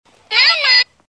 Звуки куклы: Голос игрушки, говорящей Мама